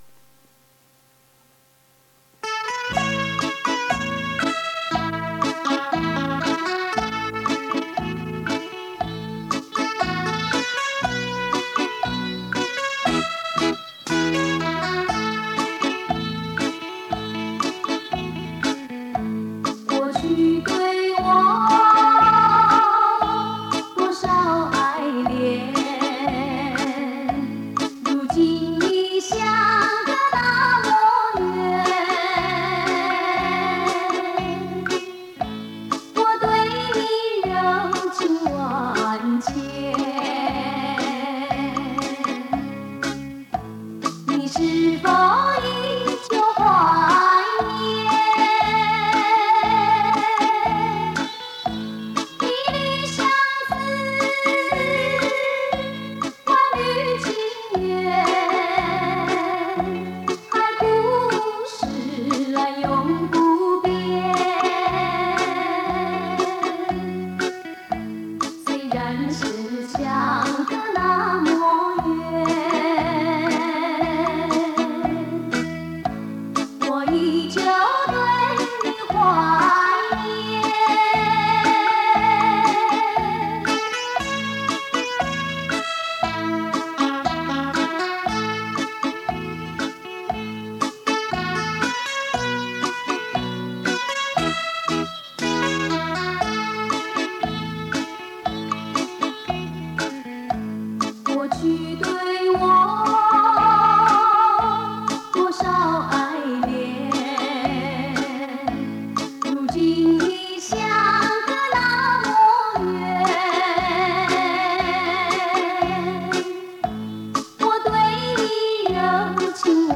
磁带数字化：2022-06-25